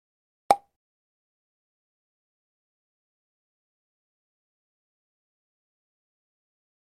Звук всплывающего уведомления